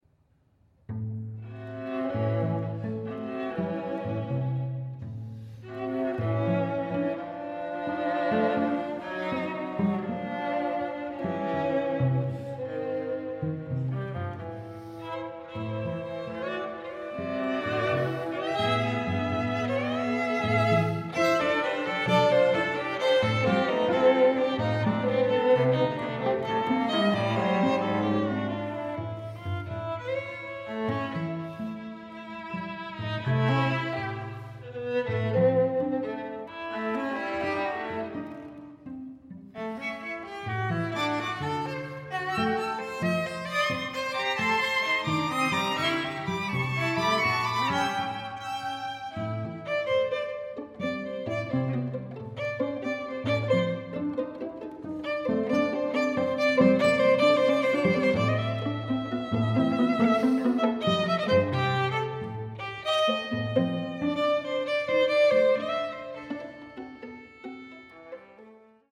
Longtime Duke University string quartet in residence